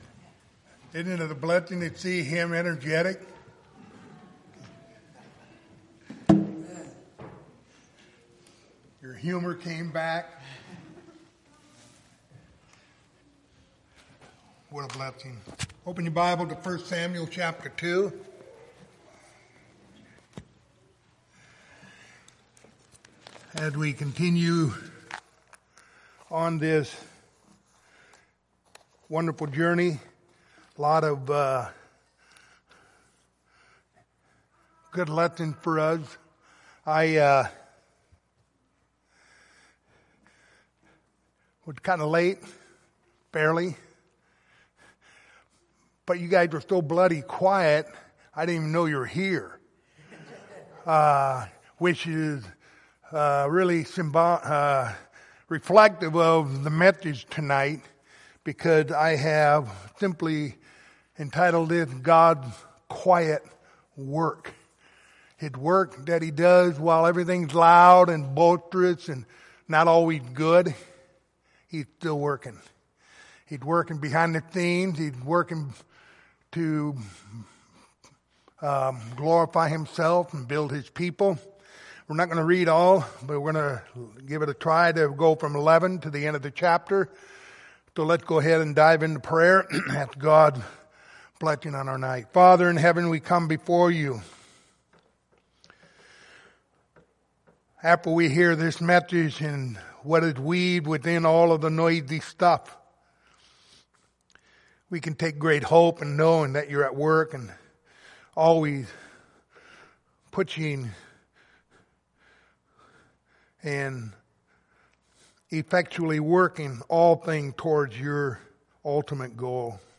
Passage: 1 Samuel 2:11-36 Service Type: Wednesday Evening